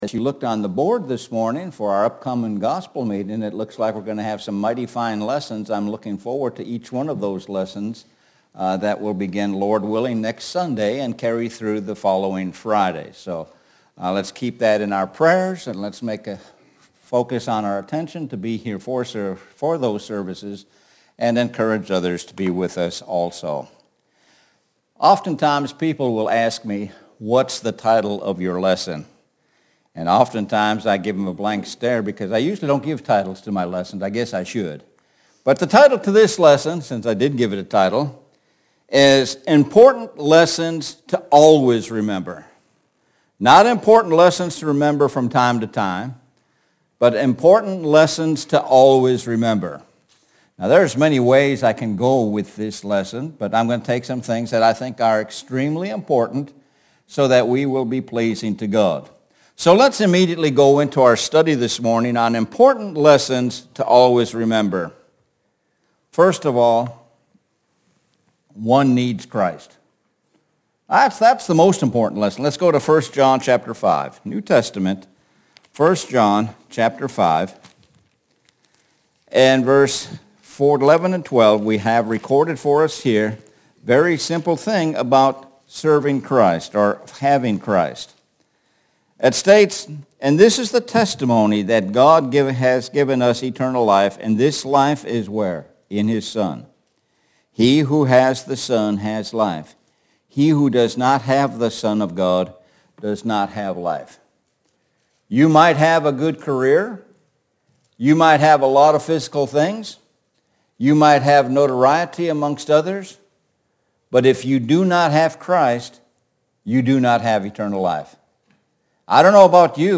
Sun AM Worship – Importat Lessons to Remember